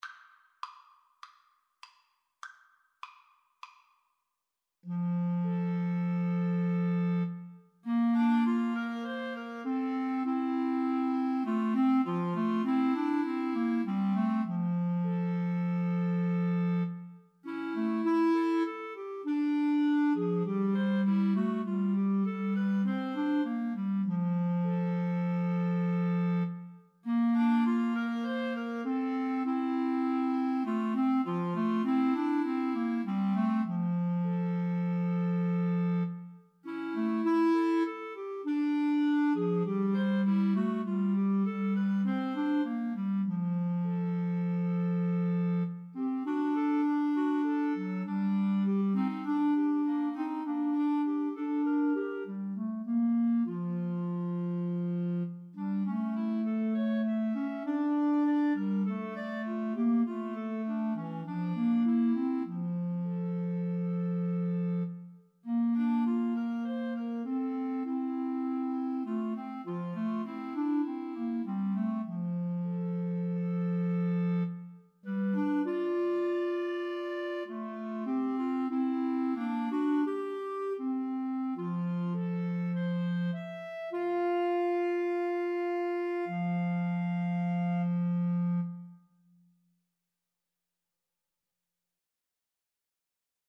Clarinet 1Clarinet 2Clarinet 3
4/4 (View more 4/4 Music)
Clarinet Trio  (View more Intermediate Clarinet Trio Music)
Classical (View more Classical Clarinet Trio Music)